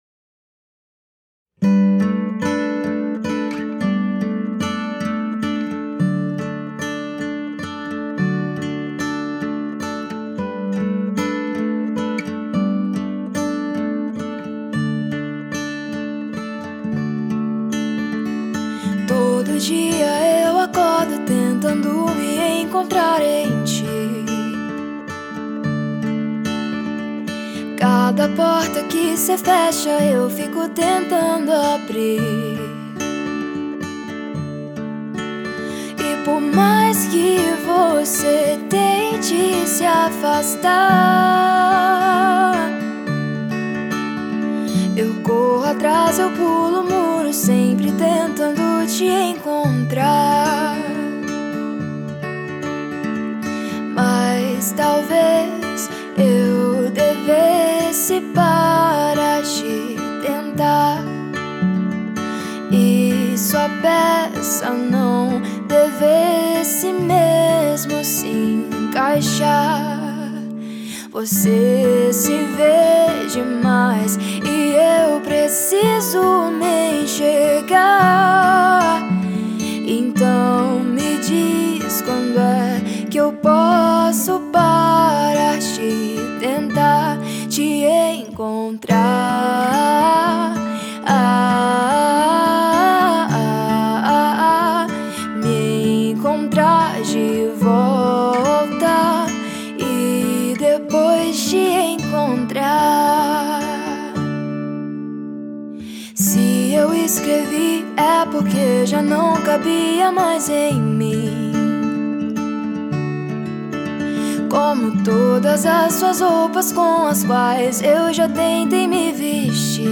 EstiloPop